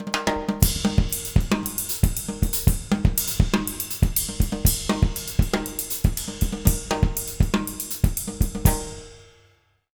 120SALSA03-L.wav